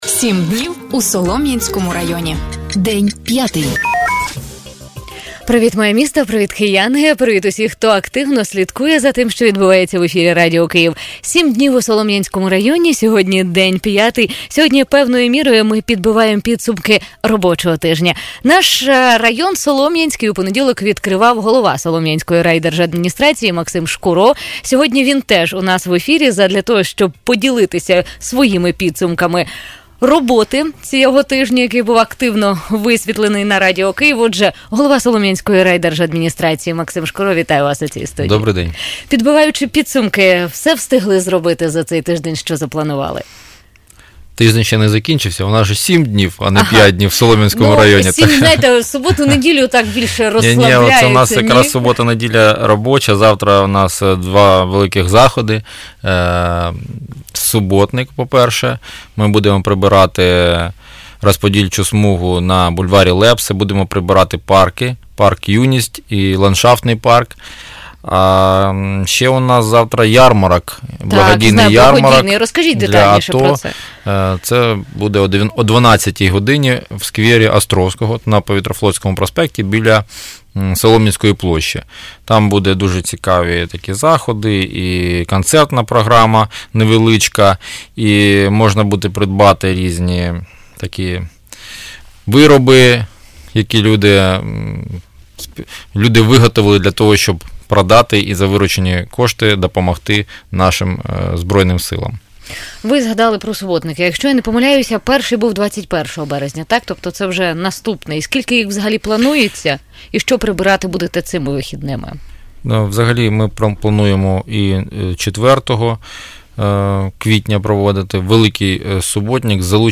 Під час ефіру керівник району Максим Шкуро відповів на звернення жителів, які надійшли до радіостанції.